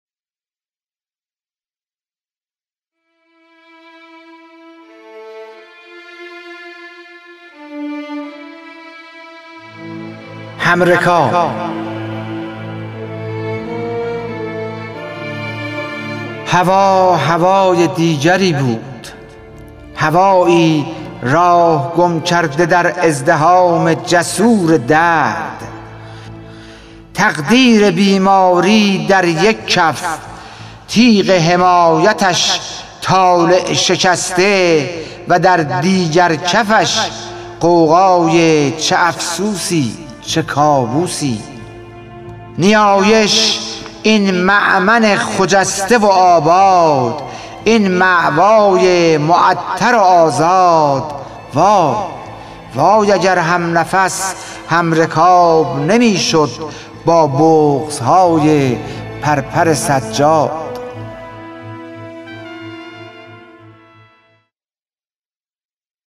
خوانش شعر سپید عاشورایی / ۶